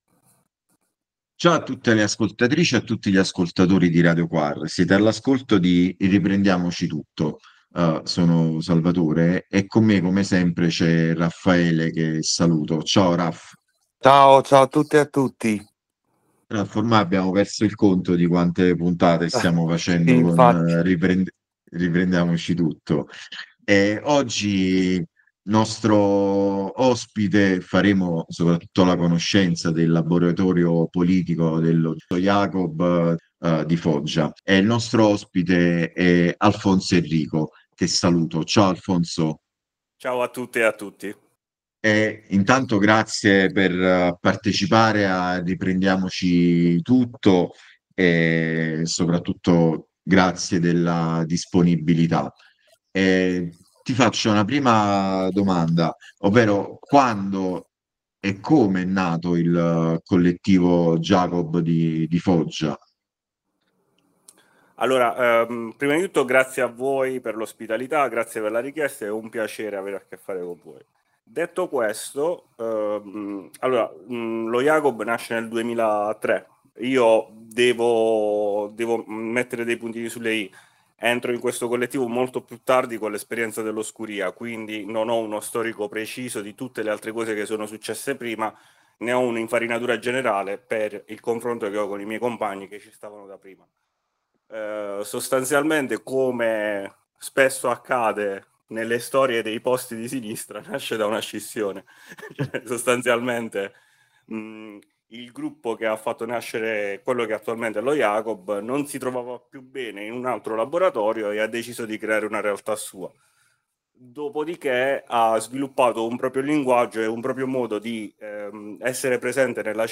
Riprendiamoci Tutto Intervista